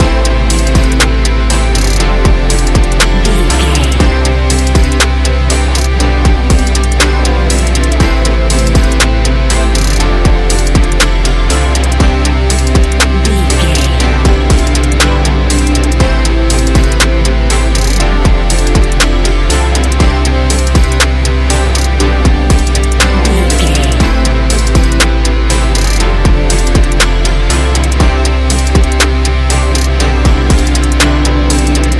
Ionian/Major
ambient
electronic
new age
downtempo
pads
drone